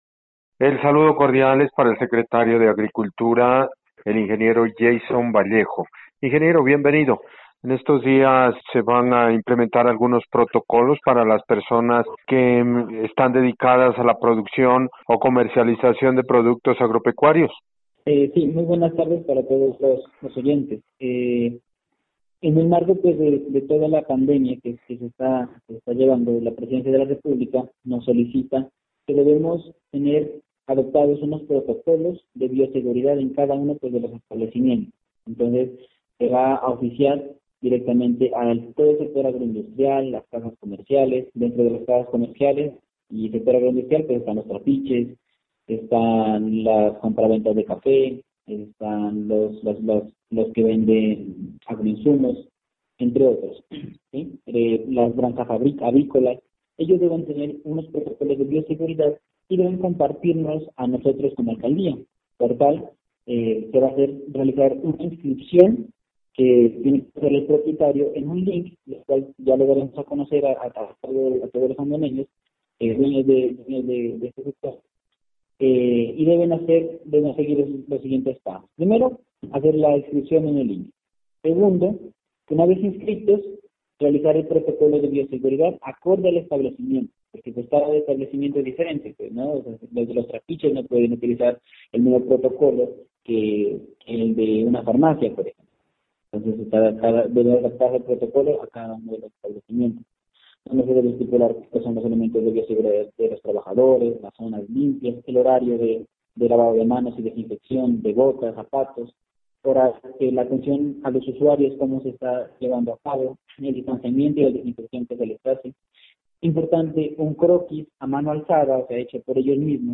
Secretario de agricultura Yeison Vallejos Almeida